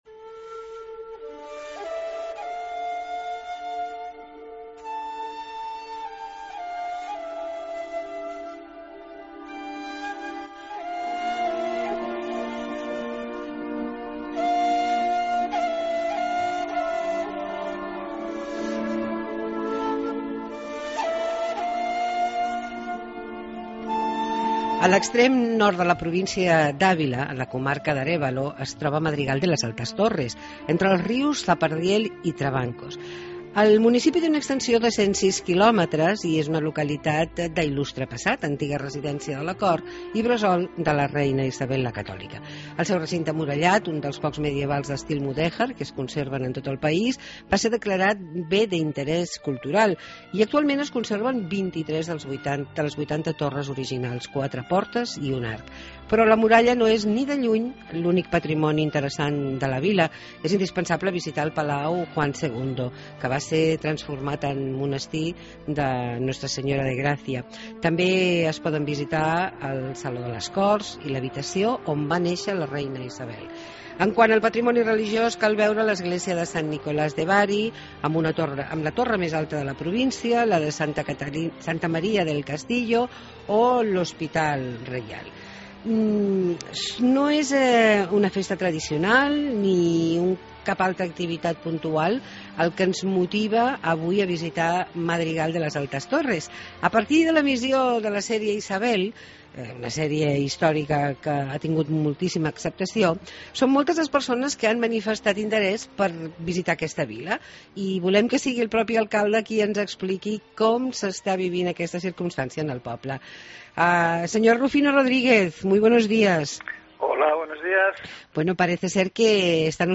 Hablamos con el alcalde de Madrigal de las Altas Torres, Rufino Rodriguez